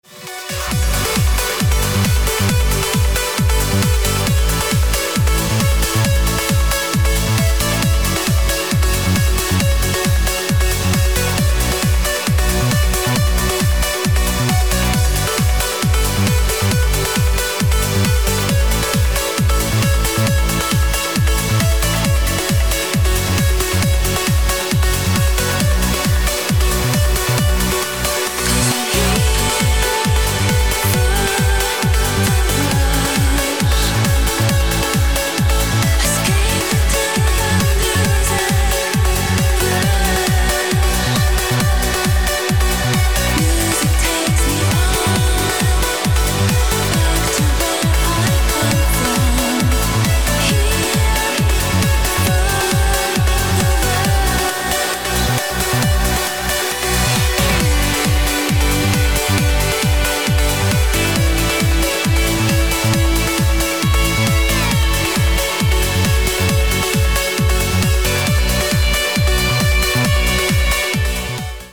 красивые
женский вокал
спокойные
club
vocal trance